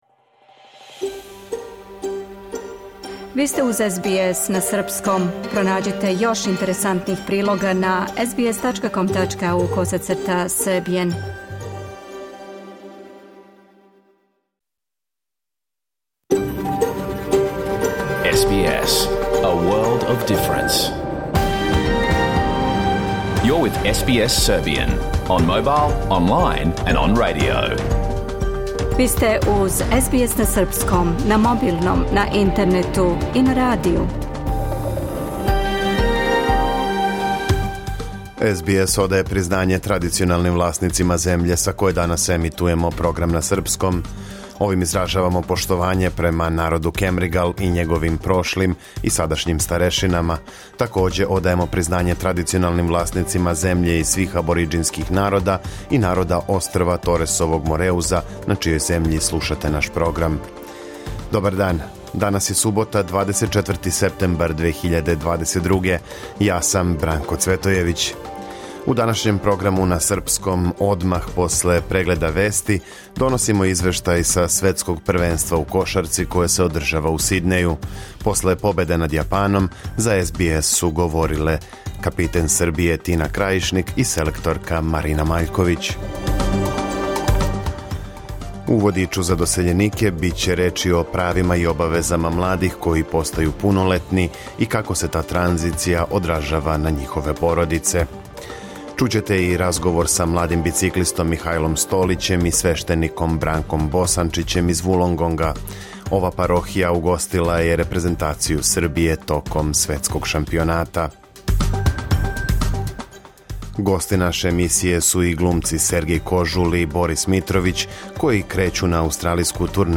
Програм емитован уживо 24. септембра 2022. годинe
Ако сте пропустили нашу емисију, сада можете да је слушате у целини као подкаст, без реклама.